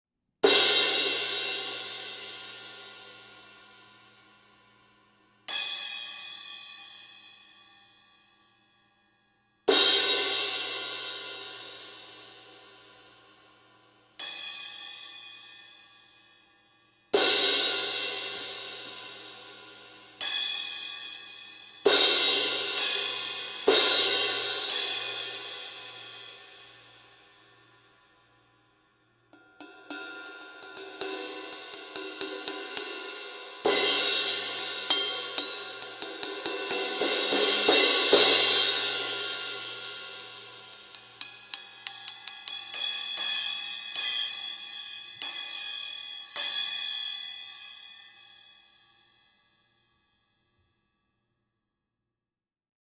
Here’s how the Praxis cymbals sound recorded side by side with our Heartbeat Classic series cymbals:
Compare Heartbeat Classic 18″ crash to Praxis 18″ crash:
18__Classic-Crash-_-Practice-Crash.mp3